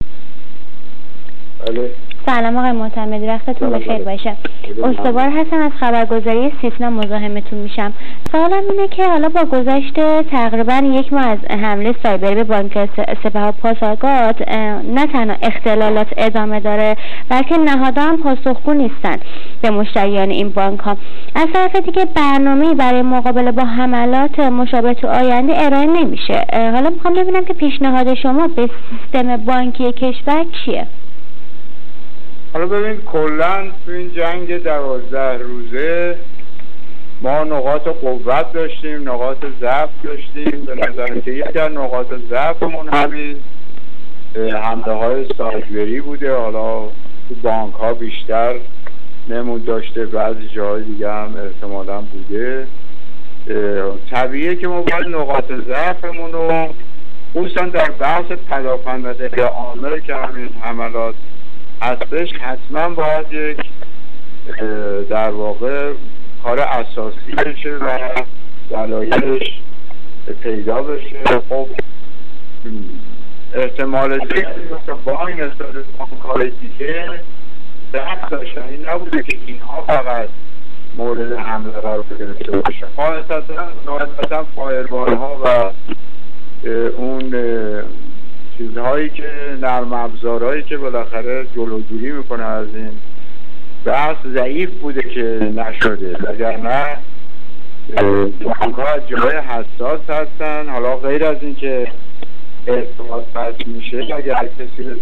احمد معتمدی، وزیر ارتباطات و فناوری اطلاعات دولت اصلاحات، در گفت‌وگو با خبرنگار سیتنا درباره حملات سایبری اخیر به بانک‌های سپه و پاسارگاد، گفت: این حملات نشان داد که یکی از ضعیف‌ترین بخش‌ها در جنگ سایبری اخیر، پدافند غیرعامل کشور بوده است.